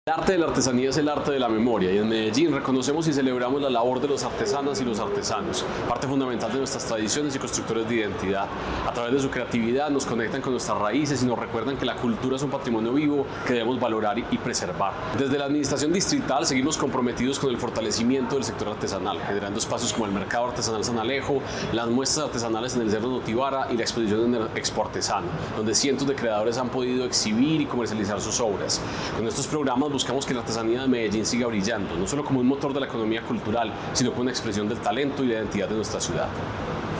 Palabras de Santiago Silva Jaramillo, secretario de Cultura Ciudadana Desde 1978, cada 19 de marzo se celebra el Día del Artesano, una fecha para enaltecer a las mujeres y hombres que, con su talento y dedicación, mantienen vivas las tradiciones.